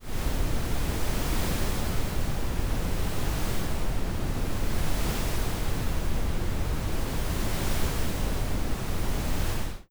OceanWaves.wav